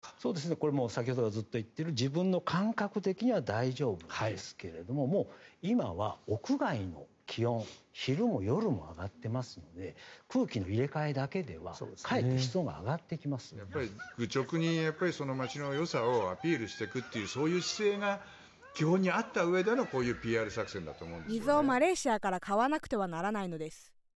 sample_diarization_japanese.mp3